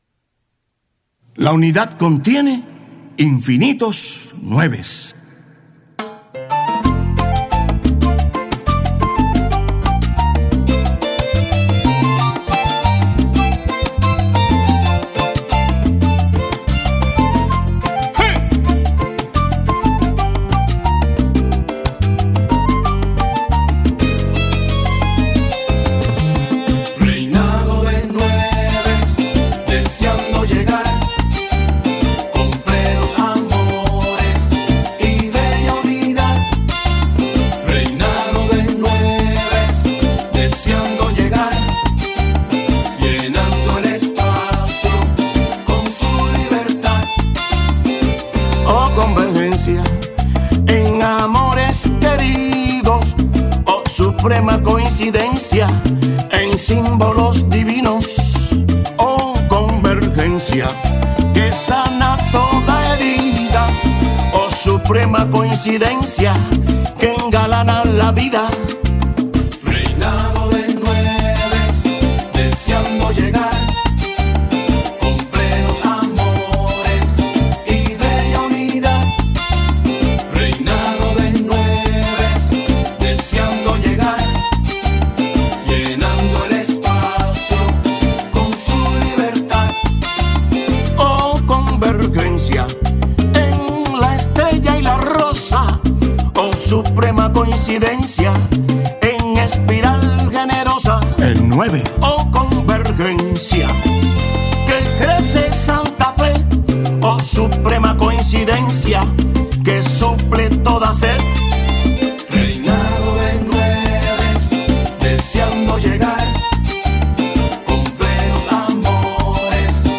Esta campanita presenta algunas reflexiones acerca de la muerte y resurrección de Jesús y recalca Su amor y sus llamados a nuestra conversión. La alegre canción “Reinado de nueves” resume aspectos esenciales del relato y se puede escuchar aquí: